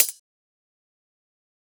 menu-charts-click.wav